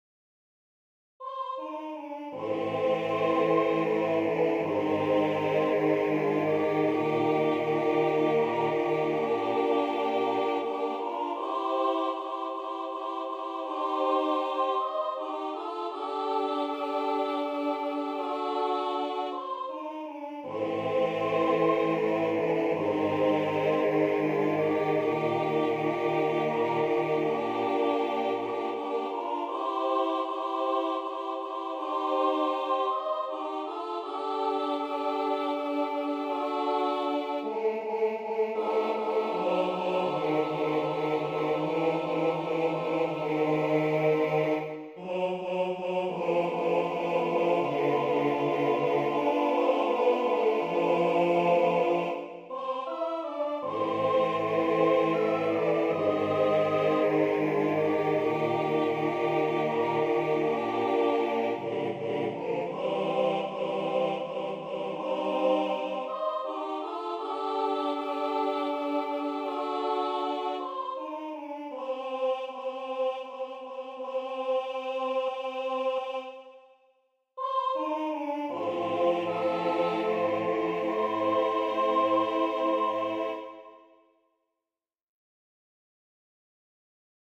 Number of voices: 4vv Voicing: SATB Genre: Sacred, Partsong
Instruments: A cappella